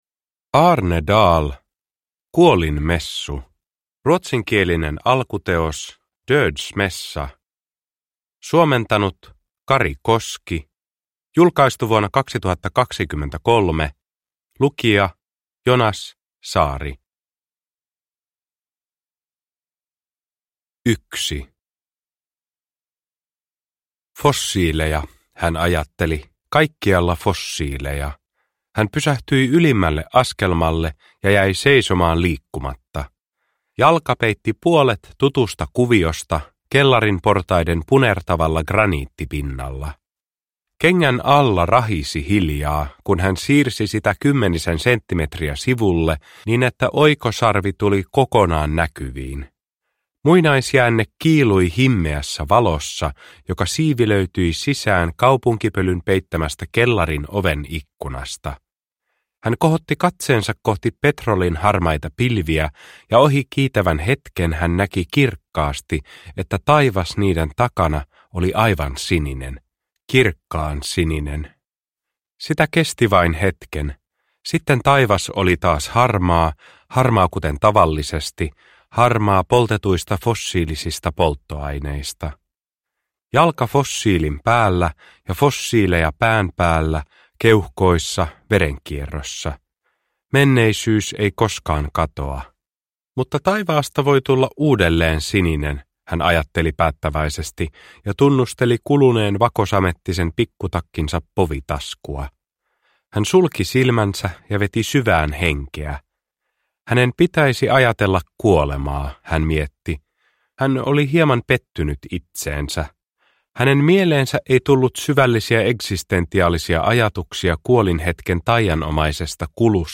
Kuolinmessu – Ljudbok – Laddas ner